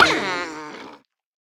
Minecraft Version Minecraft Version 1.21.5 Latest Release | Latest Snapshot 1.21.5 / assets / minecraft / sounds / mob / armadillo / death2.ogg Compare With Compare With Latest Release | Latest Snapshot